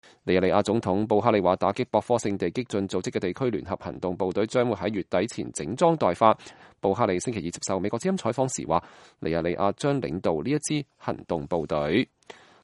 布哈里接受美國之音採訪